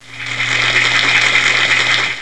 Applause5
applause5.wav